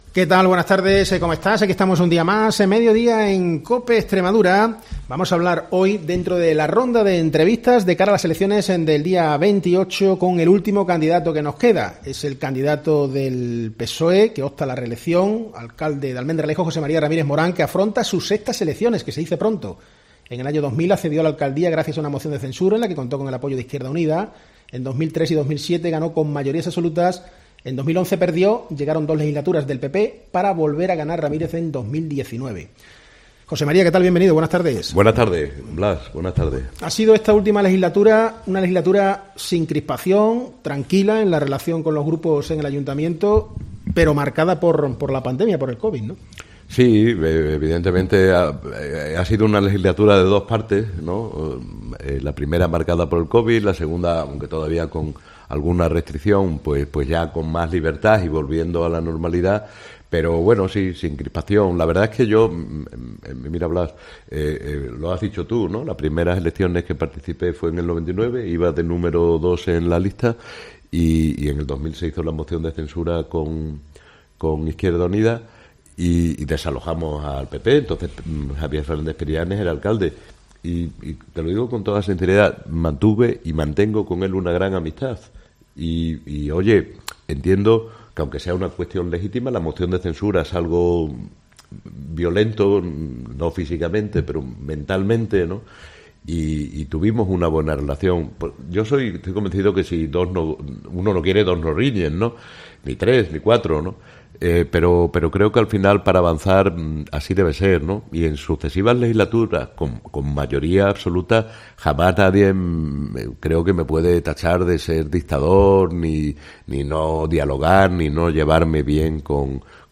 Entrevista al candidato del PSOE a la Alcaldía de Almendralejo, José María Ramírez
Ramírez mantiene en la candidatura a los diez concejales que forman parte del gobierno local. El PSOE aspira a lograr mayoría absoluta, para no depender de acuerdos con otros partidos. En COPE, hemos hablado con José María Ramírez.